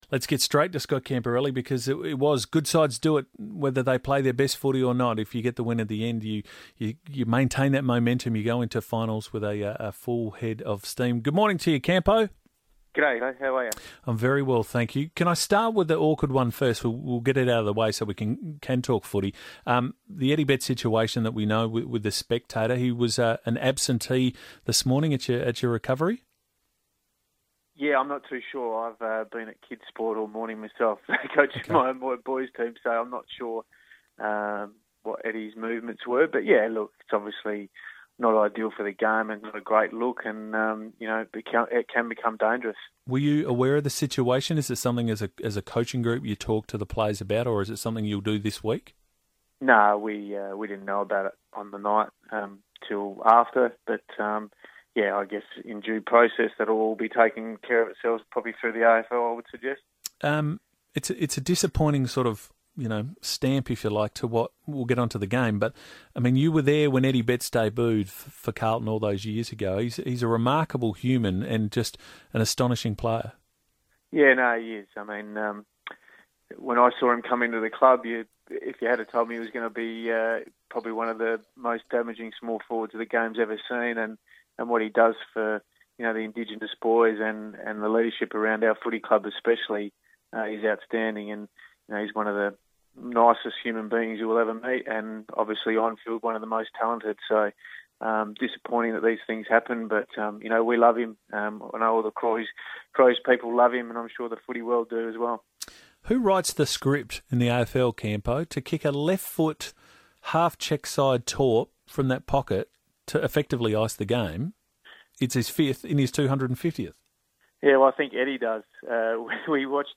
Crows assistant coach Scott Camporeale talks to FIVEaa following Adelaide's Showdown XLI victory